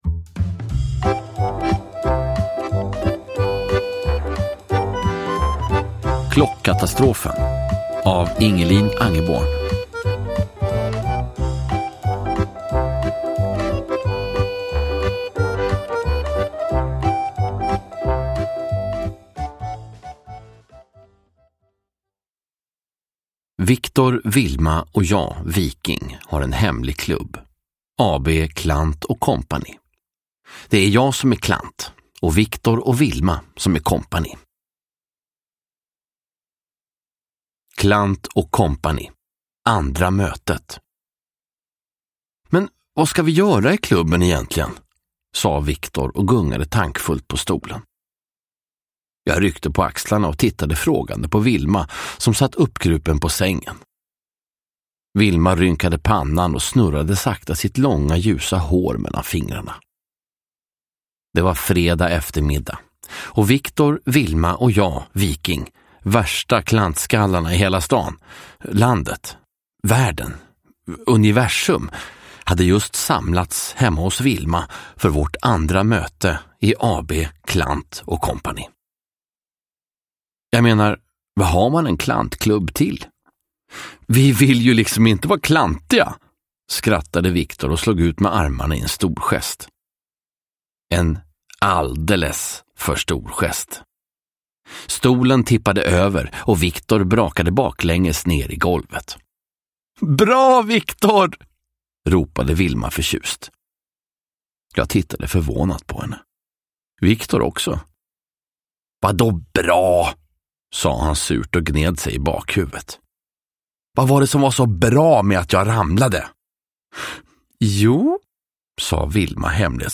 Klockkatastrofen – Ljudbok – Laddas ner
Uppläsare: